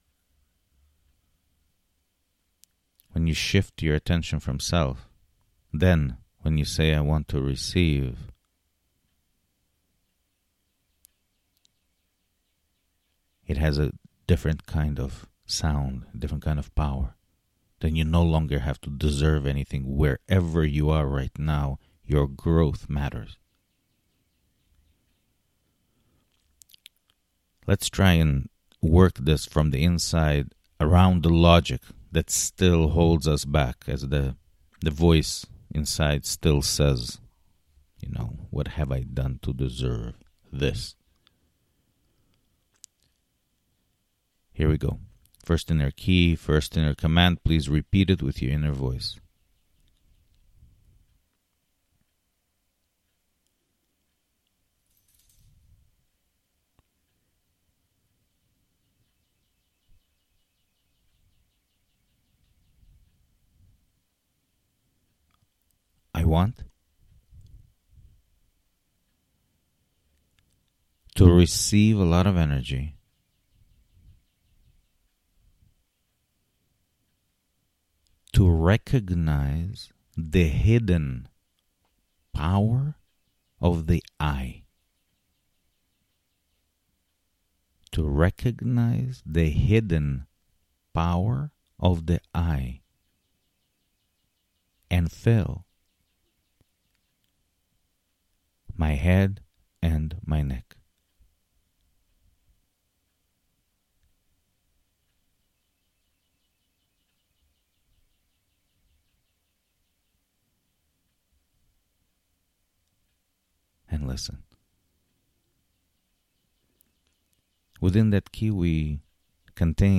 Full Version Click Here for MP3 MEDITATION Click Here for MP3 [HOME]